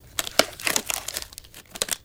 Rubber Glove Take Off From Hand